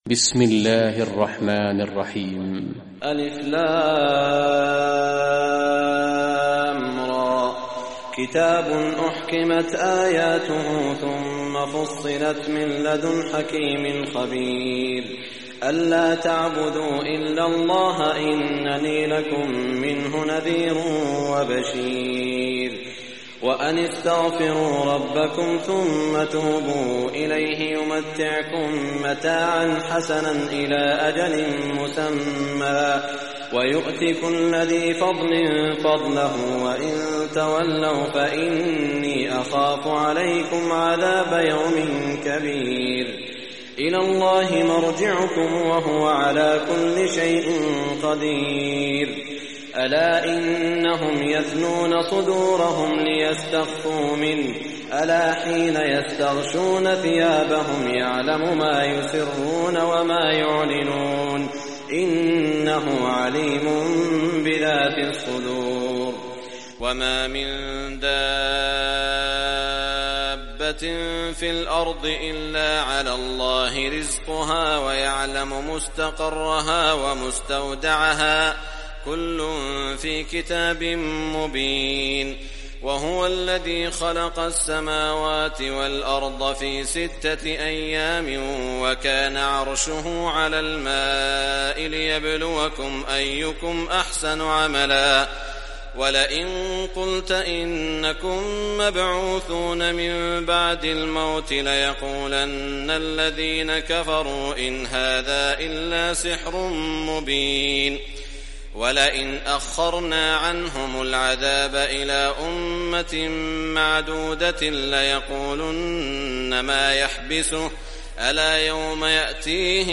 Surah Hud, listen or play online mp3 tilawat / recitation in Arabic in the beautiful voice of Sheikh Saud Al Shuraim.